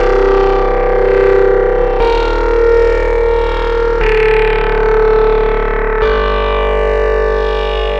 Rusty Saw.wav